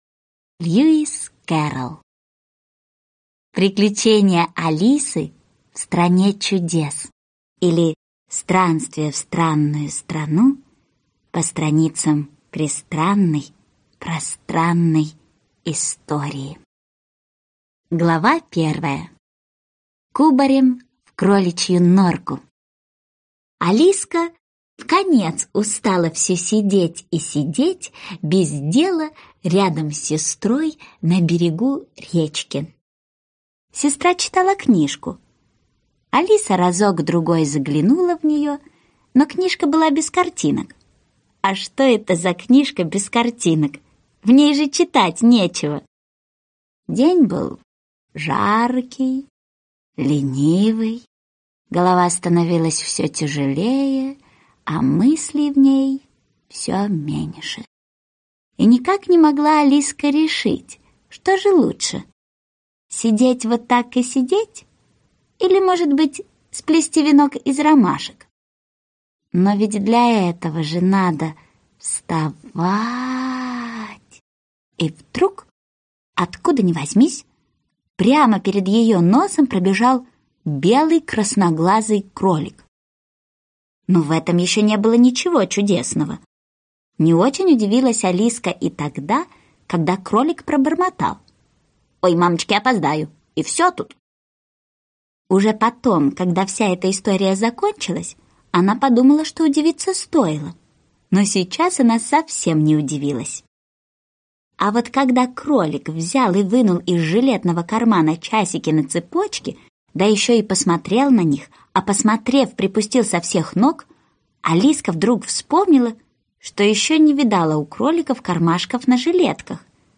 Аудиокнига Алиса в Стране чудес - купить, скачать и слушать онлайн | КнигоПоиск
Аудиокнига «Алиса в Стране чудес» в интернет-магазине КнигоПоиск ✅ Сказки в аудиоформате ✅ Скачать Алиса в Стране чудес в mp3 или слушать онлайн